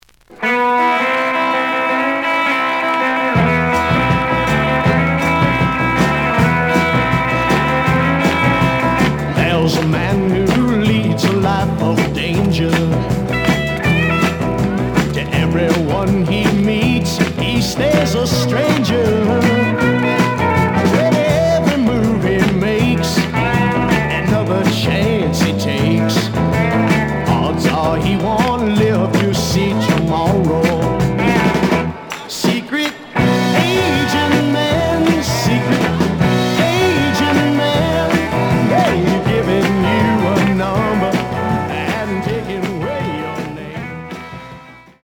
The audio sample is recorded from the actual item.
●Genre: Soul, 60's Soul
A side plays good.